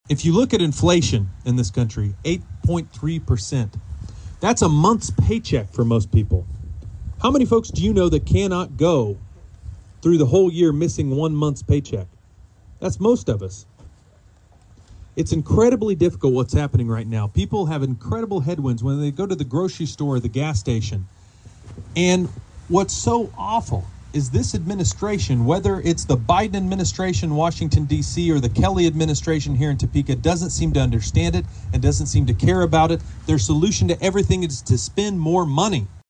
Republican gubernatorial candidate Derek Schmidt headlined a Kansas GOP bus tour stop at the Lyon County Fairgrounds alongside US House Second District candidate Jake LaTurner and US Senator Roger Marshall on Saturday.